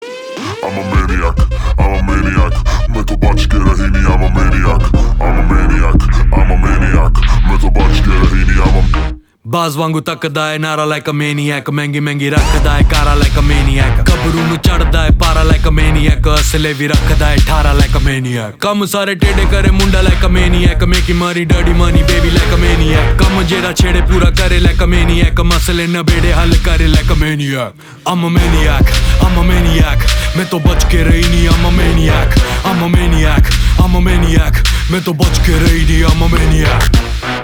Party Song